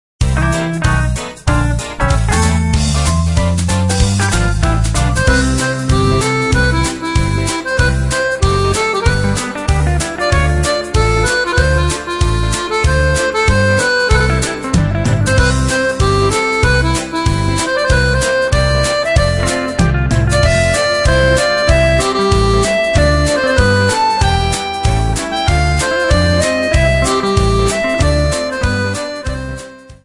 Accordion Music 3 CD Set.